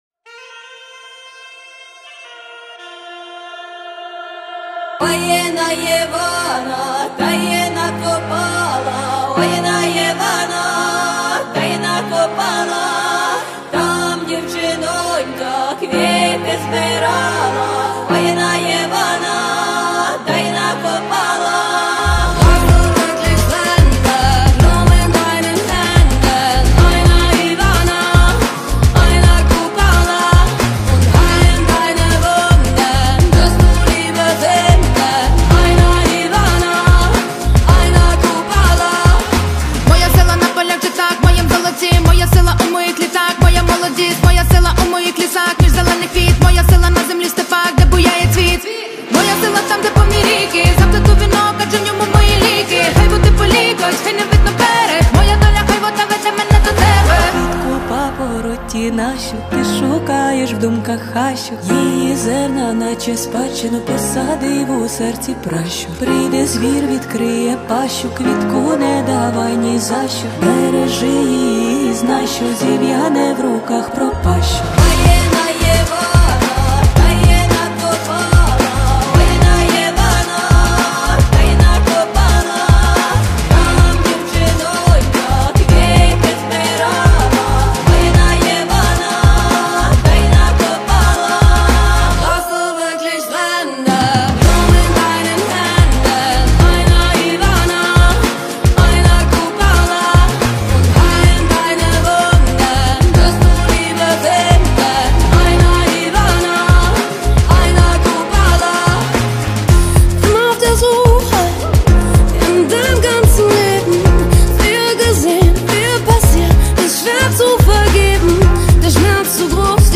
Жанр: Реп